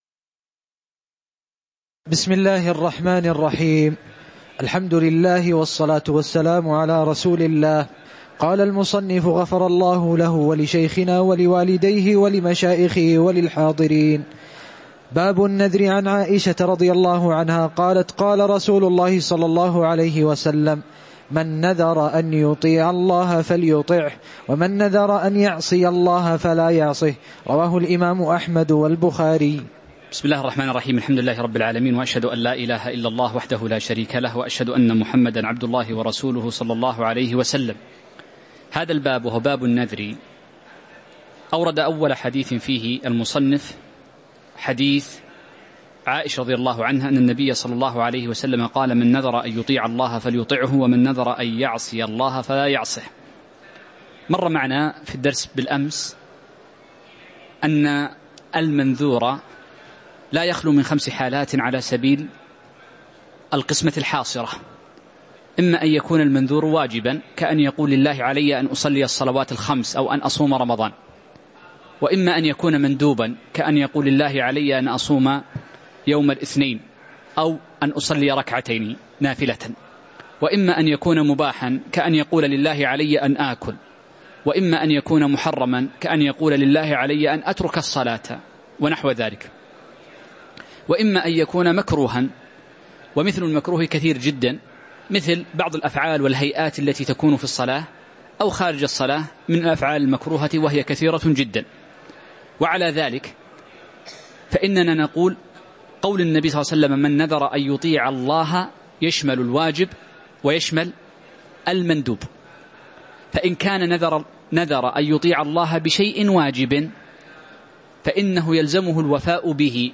تاريخ النشر ١٢ ربيع الأول ١٤٤١ هـ المكان: المسجد النبوي الشيخ